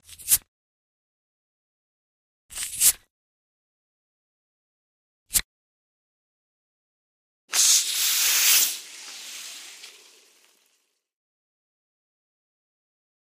Hiss | Sneak On The Lot